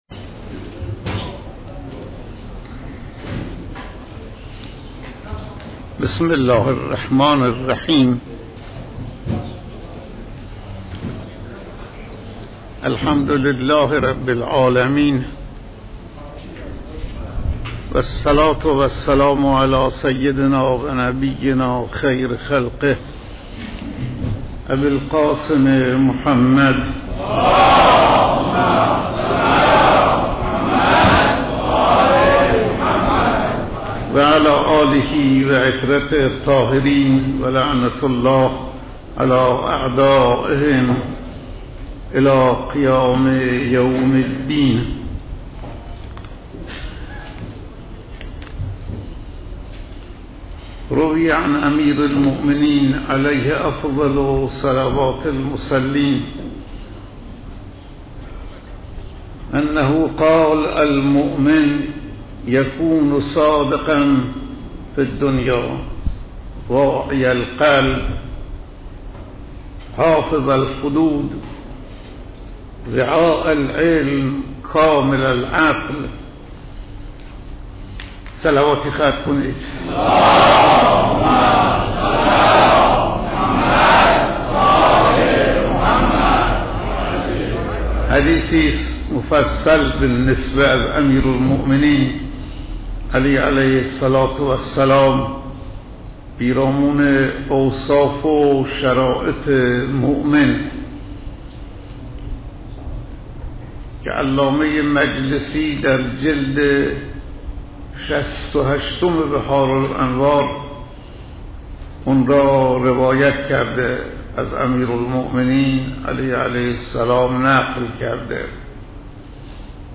سخنرانی روز ۶ ماه رمضان